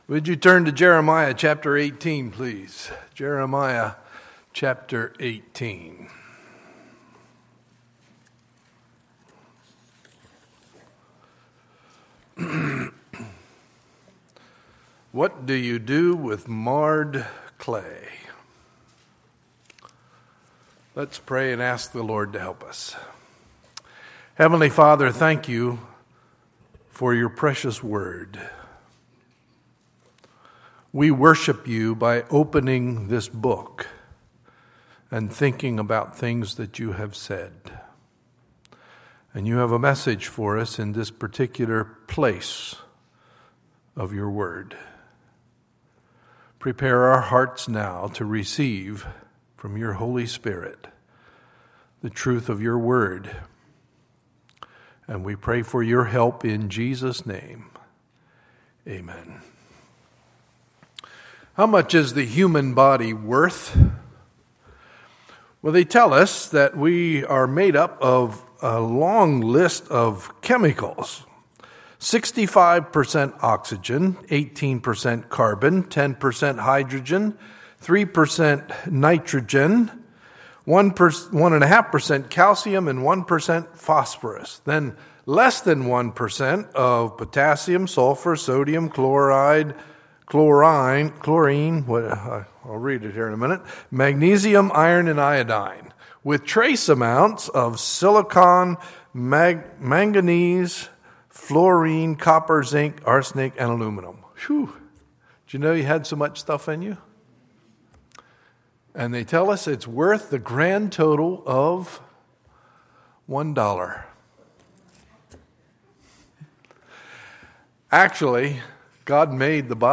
Sunday, June 7, 2015 – Sunday Morning Service